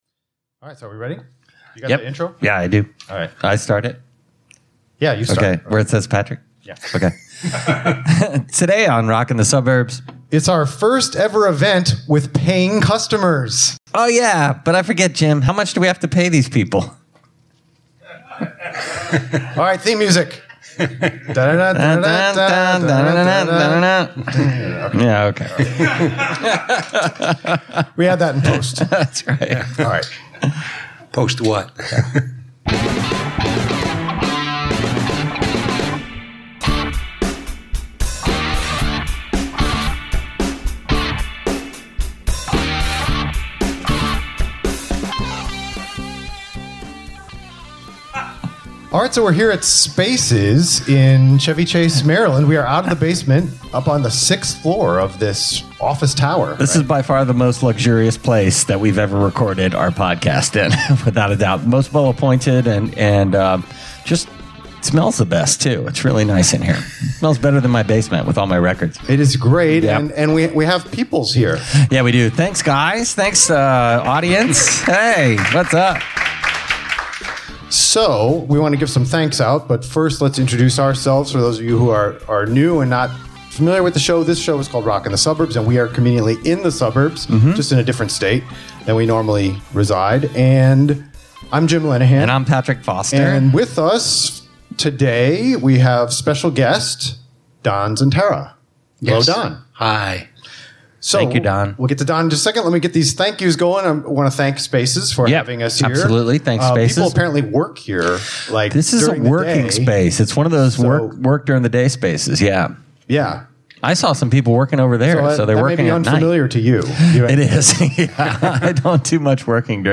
Live Show